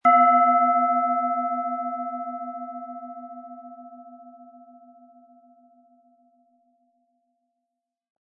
Planetenschale® Potentiale erkennen & Weibliche Kraft leben mit Lilith, Ø 10,7 cm, 100-180 Gramm inkl. Klöppel
Planetenton 1
Im Sound-Player - Jetzt reinhören können Sie den Original-Ton genau dieser Schale anhören.
SchalenformBihar
MaterialBronze